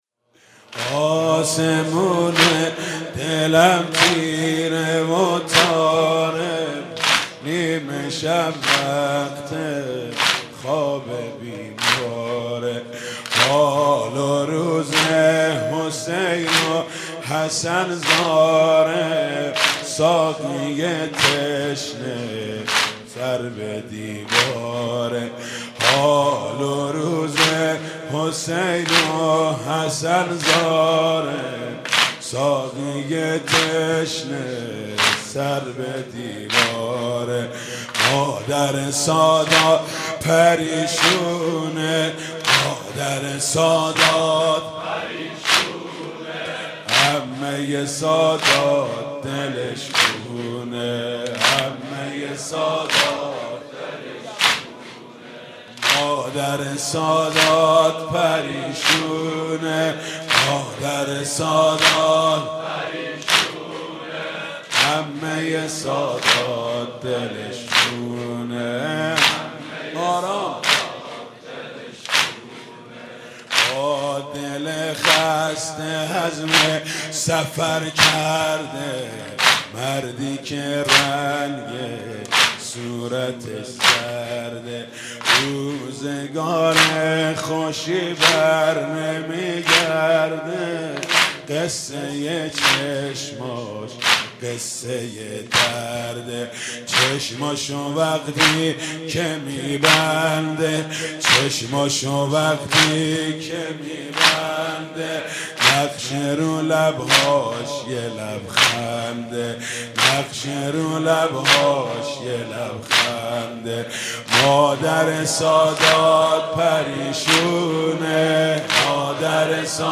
زمینه: آسمونه دلم تیره و تاره